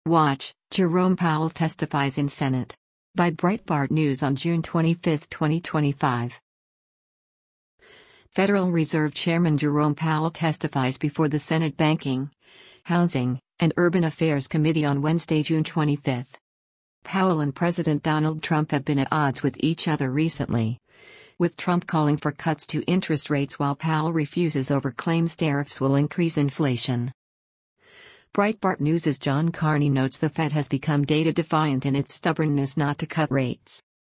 Federal Reserve Chairman Jerome Powell testifies before the Senate Banking, Housing, and Urban Affairs Committee on Wednesday, June 25.